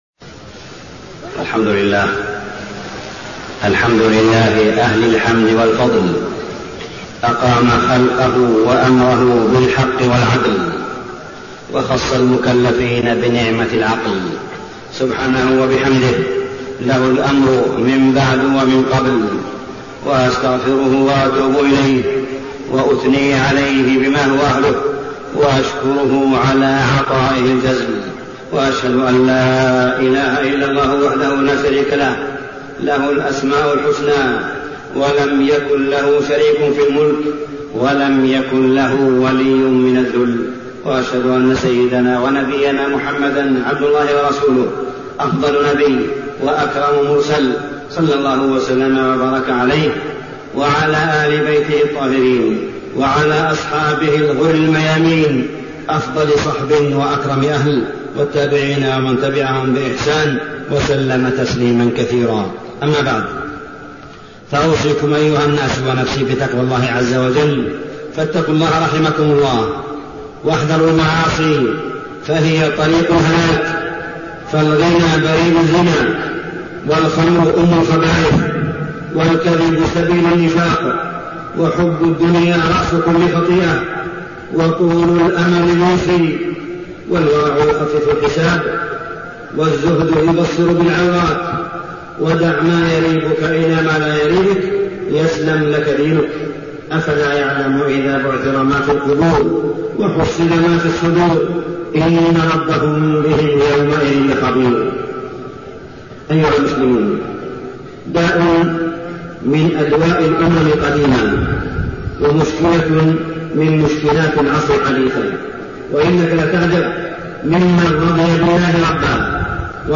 تاريخ النشر ١٤ ربيع الثاني ١٤٣٠ هـ المكان: المسجد الحرام الشيخ: معالي الشيخ أ.د. صالح بن عبدالله بن حميد معالي الشيخ أ.د. صالح بن عبدالله بن حميد التحذير من السحر والشعوذة